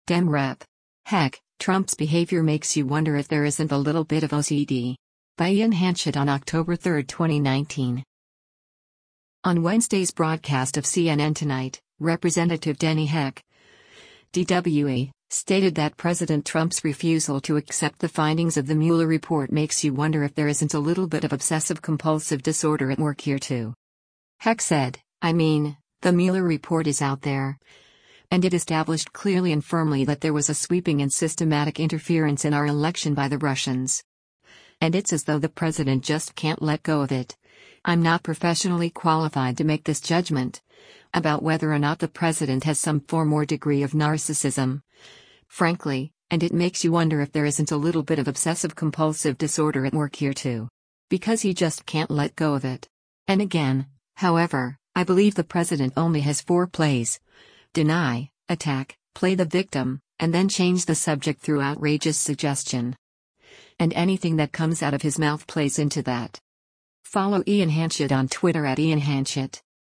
On Wednesday’s broadcast of “CNN Tonight,” Representative Denny Heck (D-WA) stated that President Trump’s refusal to accept the findings of the Mueller report “makes you wonder if there isn’t a little bit of Obsessive-Compulsive Disorder at work here too.”